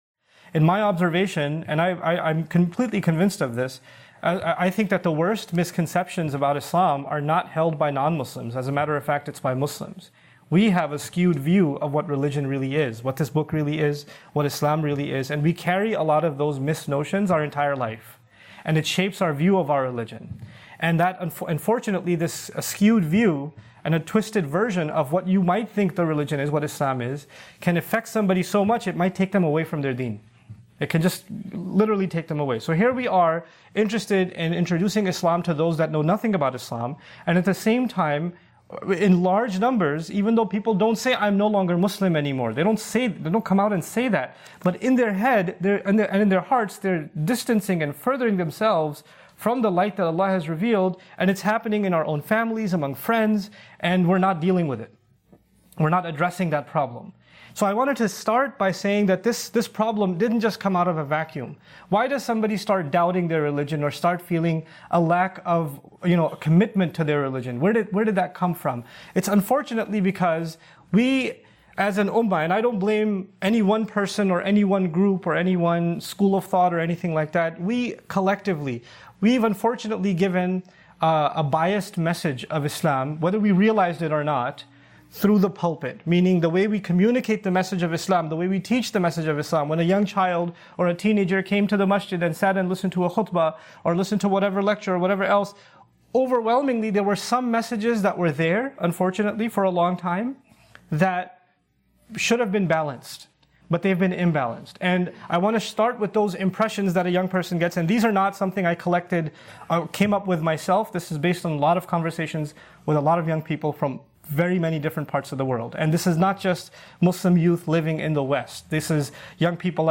A Fresh Start - Khutbah by Nouman Ali Khan.mp3